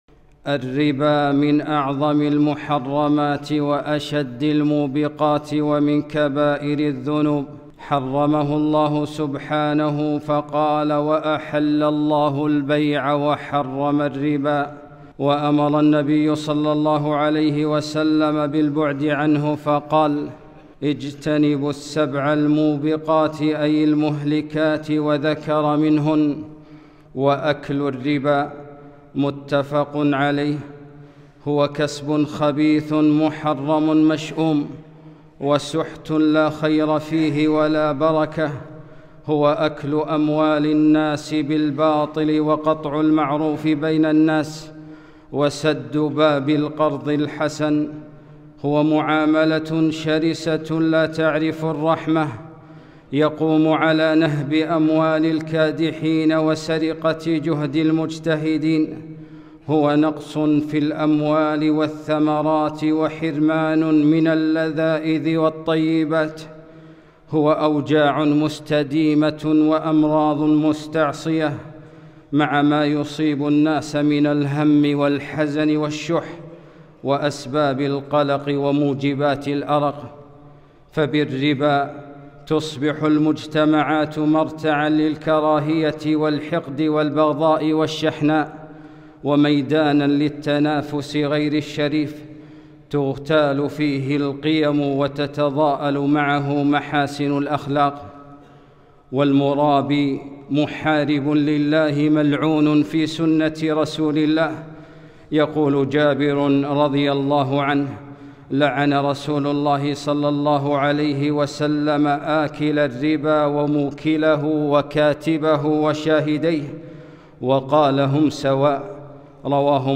خطبة - يمحق الله الربا